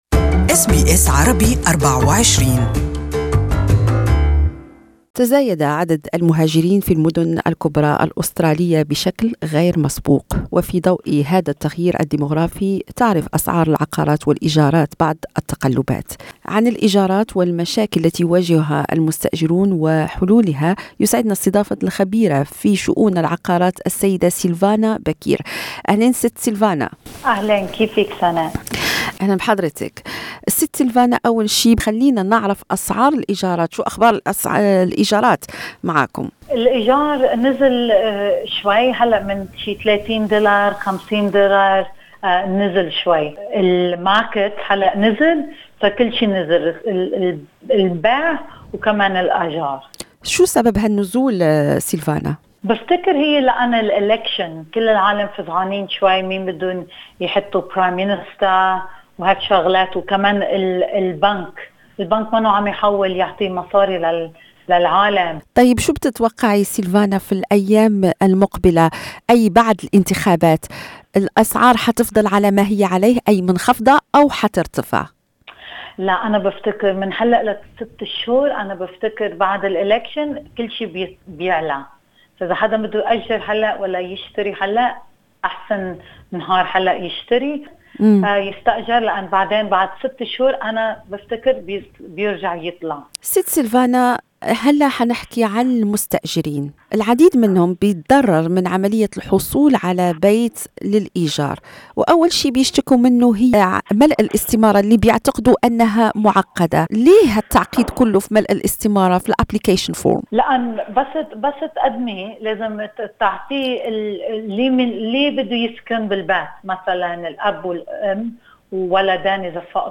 This interview is in Arabic language.